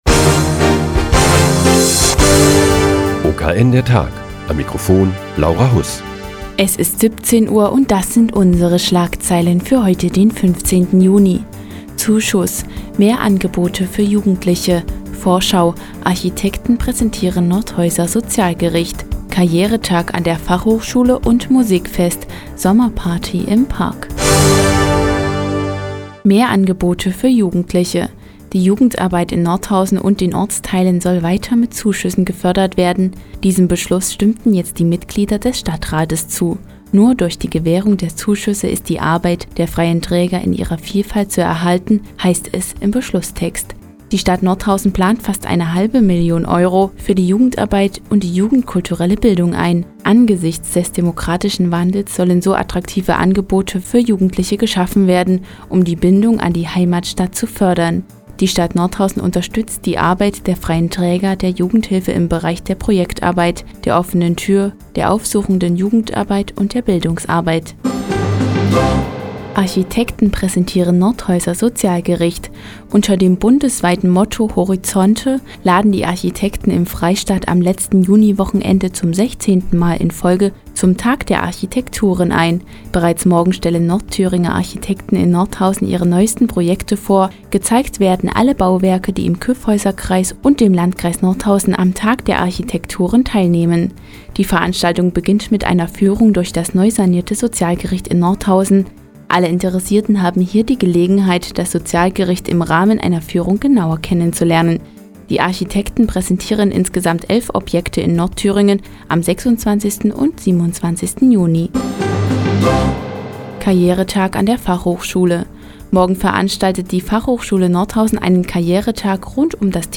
Die tägliche Nachrichtensendung des OKN ist nun auch in der nnz zu hören. Heute geht es um Zuschüsse für die Jugendarbeit in Nordhausen und den "Tag der Architektouren" am letzten Juniwochenende.